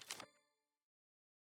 map_open_updated_1.wav